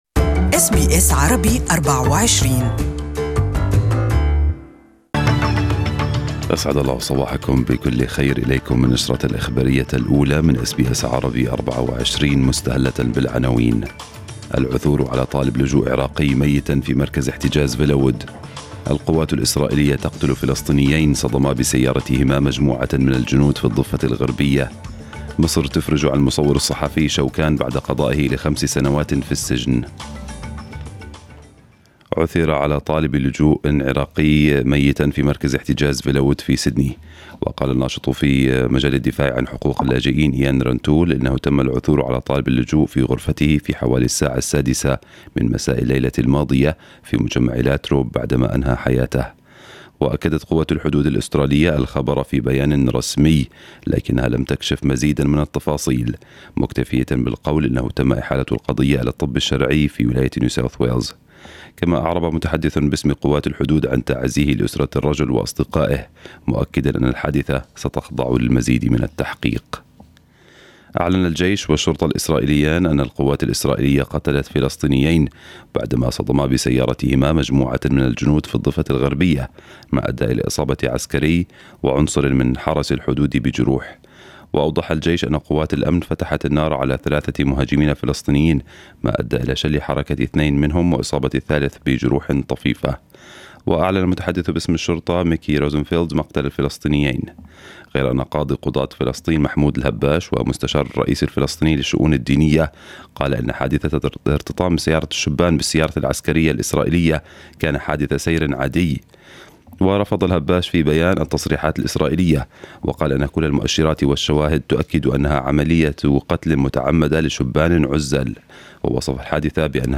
نشرة الأخبار المفصلة لهذا الصباح